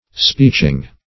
Speeching \Speech"ing\, n. The act of making a speech.